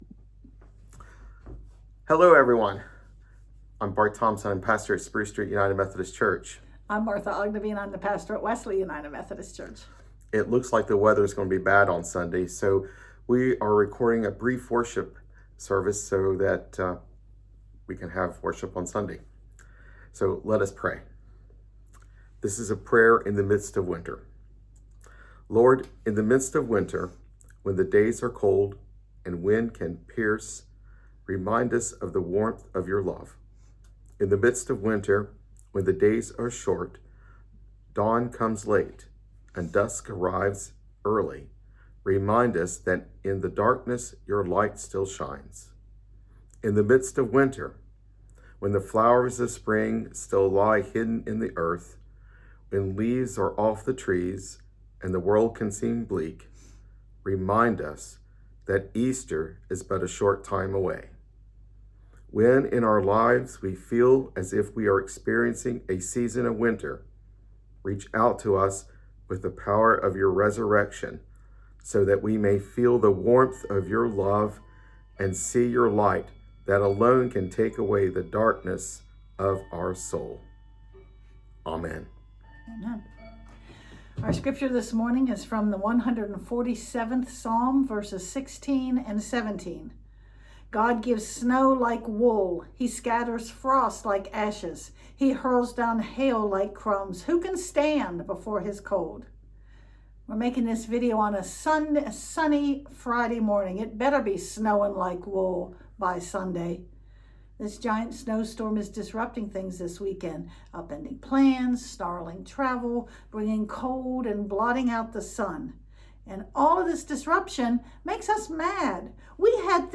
Sunday Service.mp3